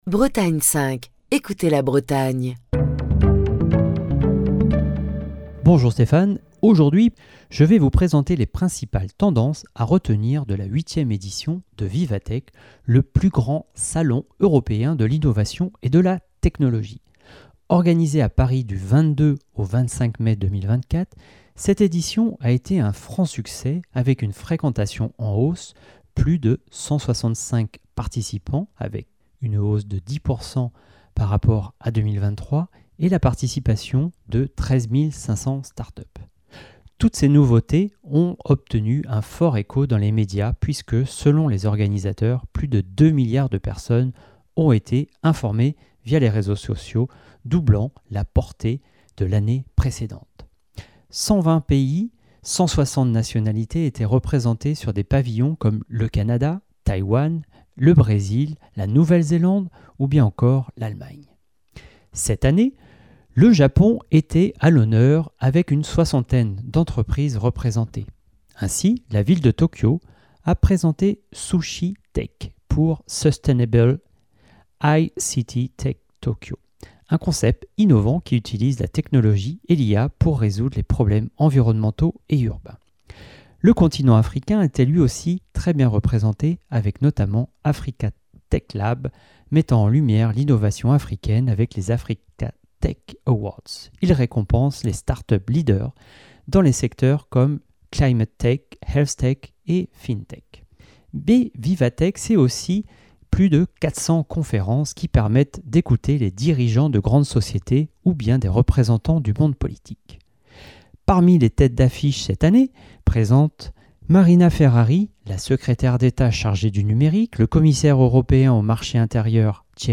Chronique du 5 juin 2024.